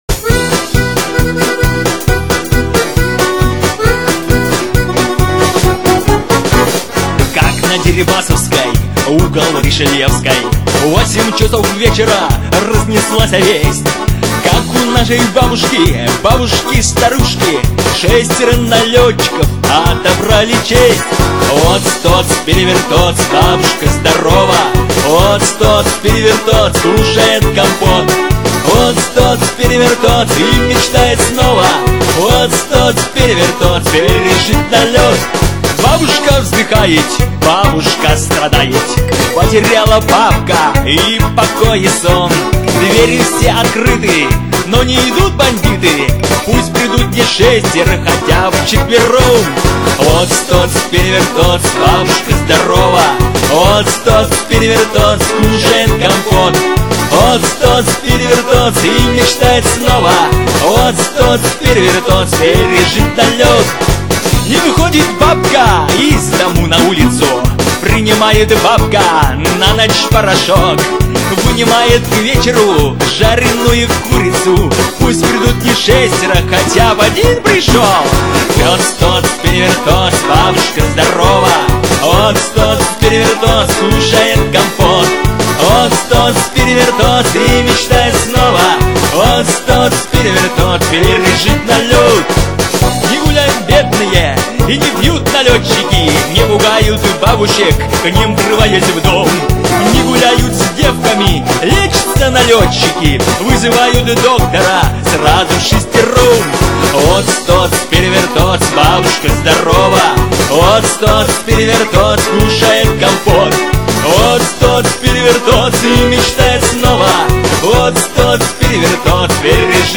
Архив ресторанной музыки